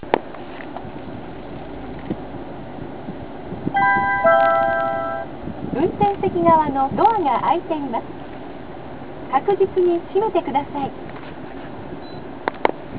半ドア警告